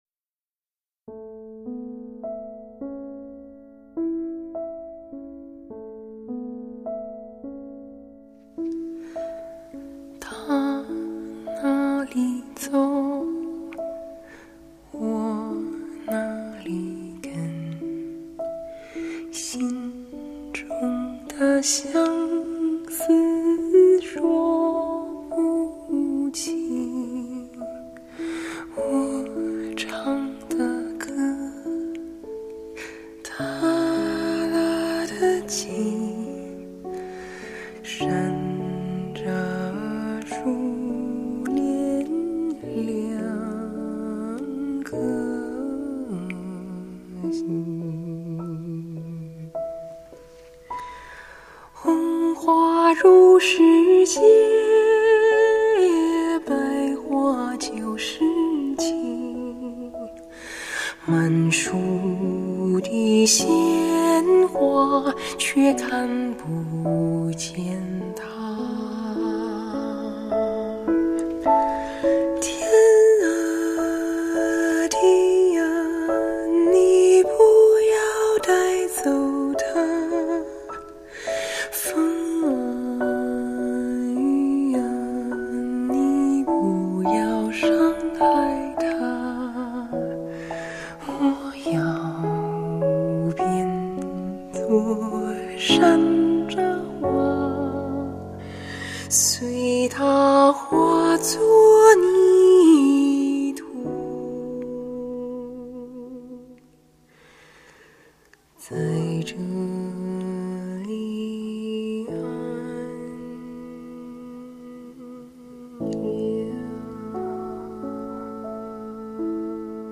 音乐风格: 中文流行
17    独唱      主题歌